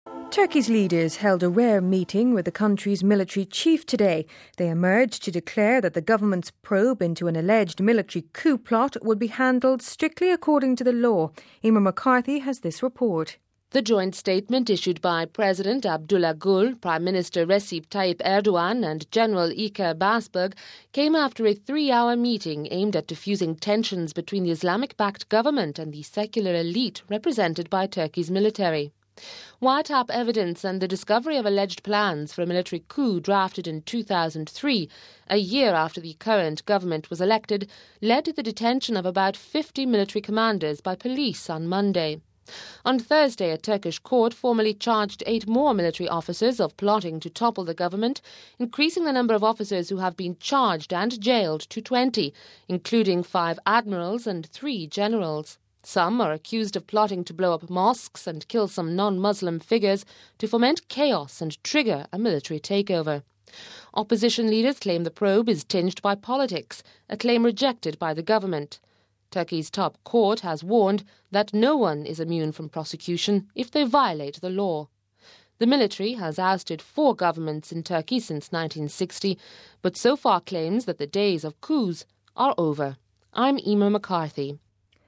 We have this report: